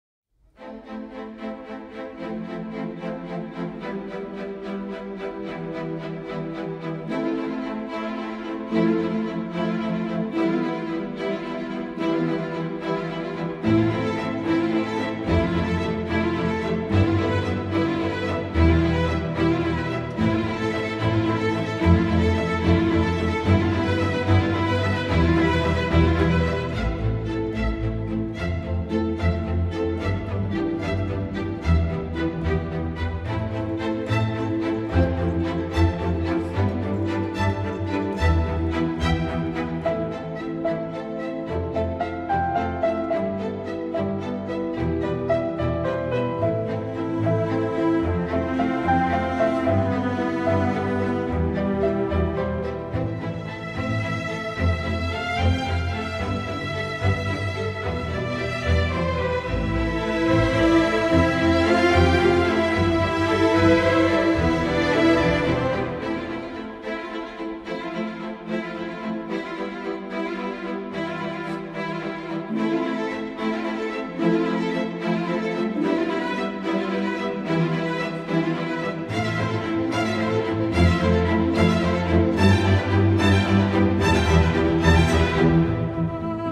una banda sonora de acentuado refinamiento clásico